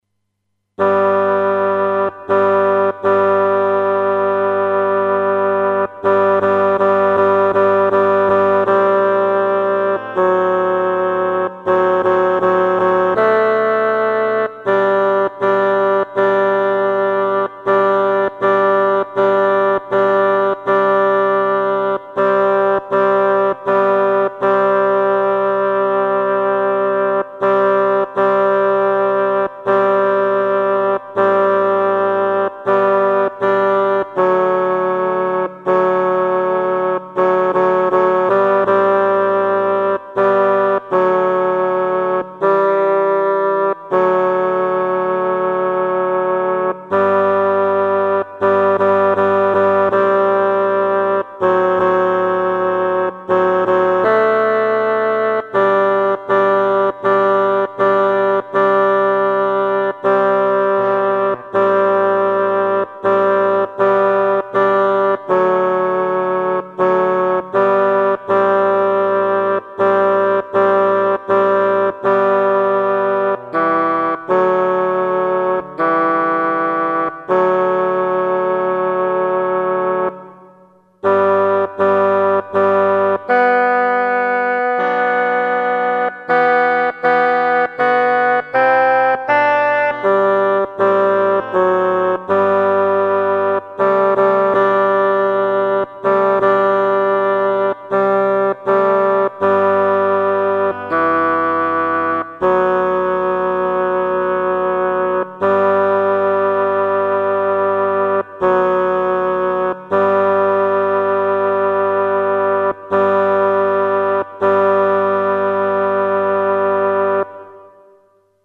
note: afin de ménager la bande passante, les fichiers en écoute immédiate sont encodés en mono, 22 KHz, 32 Kbps, ... ce qui signifie que leur qualité n'est pas "excellentissime"!
version en sol majeur
otche--sol -midi-T2.mp3